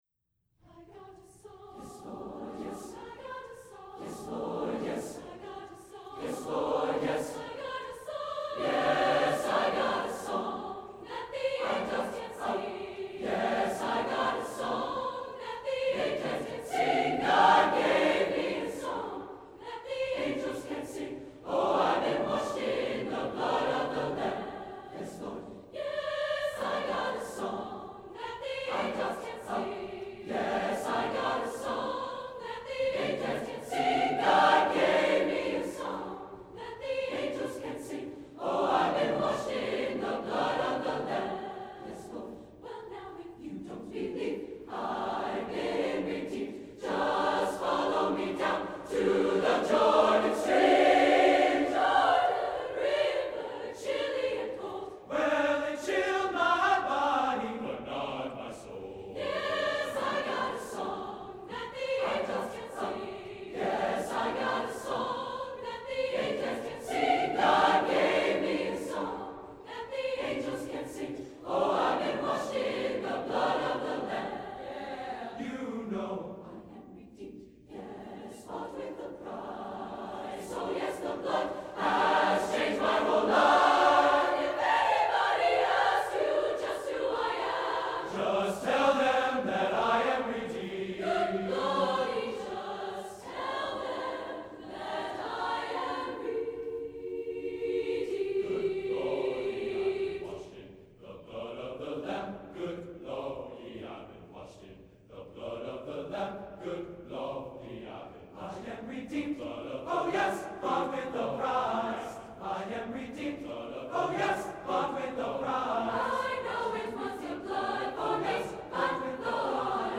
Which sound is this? Voicing: SSAATB